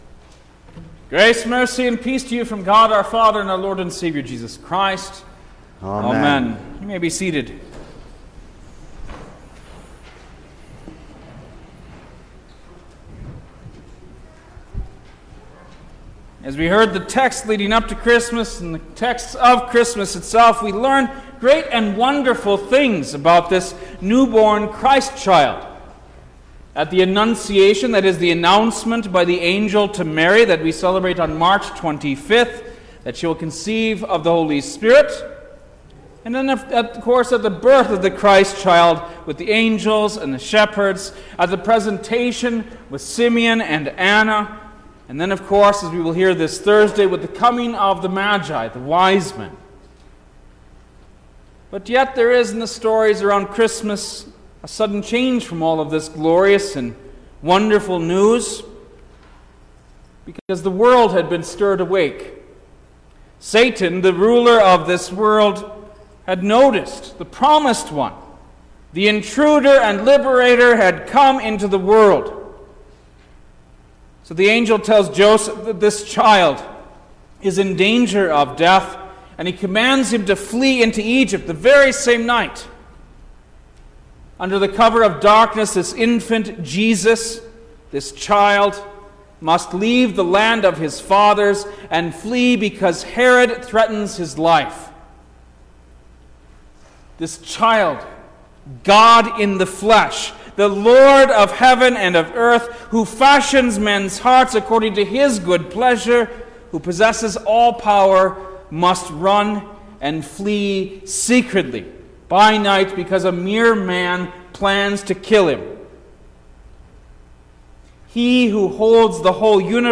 January-2_2021_Second-Sunday-After-Christmas_sermon_stereo.mp3